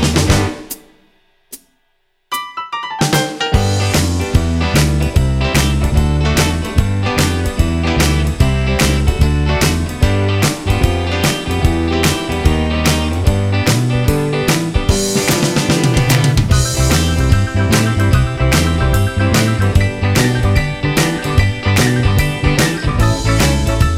no Backing Vocals Rock 'n' Roll 2:33 Buy £1.50